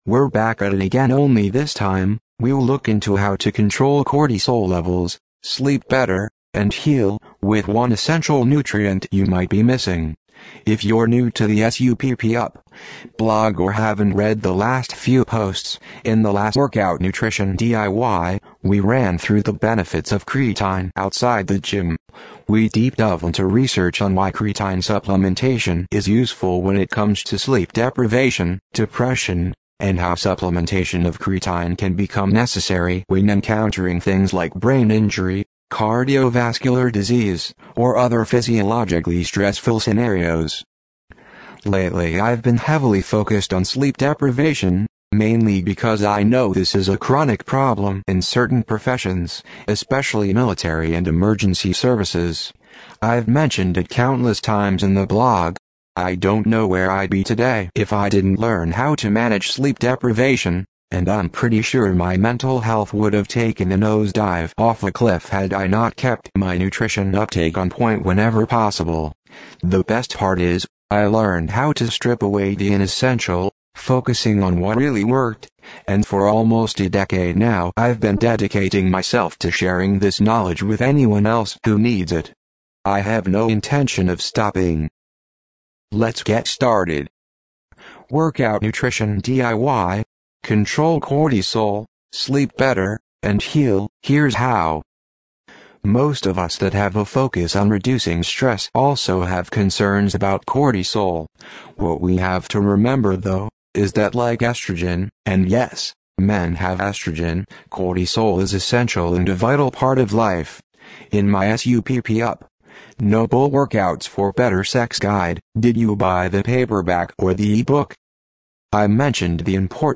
Workout-Nutrition-DIY-Control-Cortisol-Sleep-Better-and-Heal-Heres-How-SUPP-UP-TTS.mp3